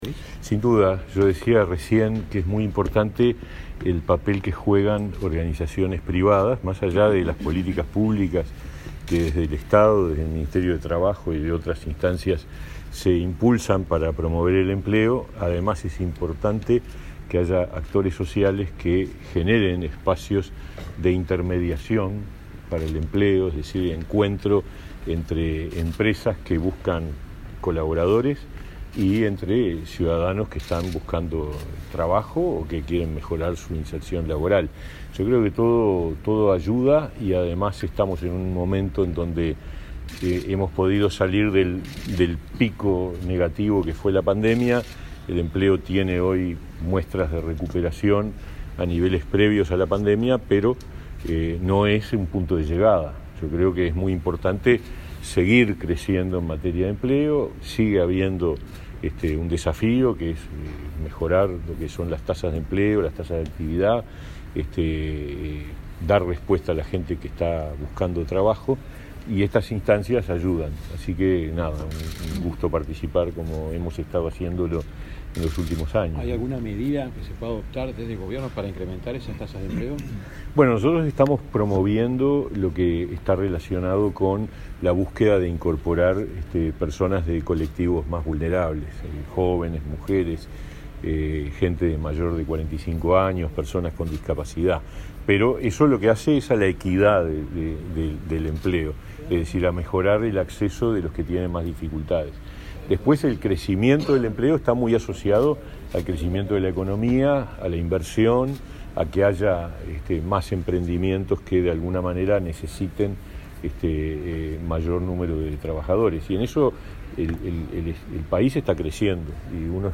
Declaraciones del ministro Mieres y el director de Trabajo, Federico Davedere 28/09/2022 Compartir Facebook X Copiar enlace WhatsApp LinkedIn El ministro de Trabajo y Seguridad Social, Pablo Mieres, y el director nacional de Trabajo, Federico Davedere, dialogaron con la prensa luego de participar en la inauguración del Recruiting Day 2022.